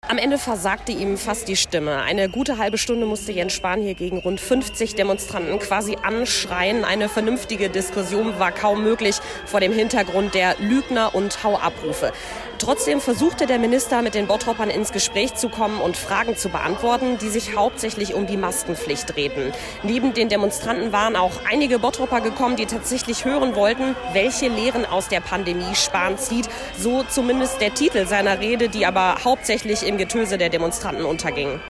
Lautstarker Protest bei Besuch von Jens Spahn in Bottrop
Pfiffe und "Hau ab"-Rufe für den Bundesgesundheitsminister - Oberbürgermeister Bernd Tischler kritisiert Störenfriede.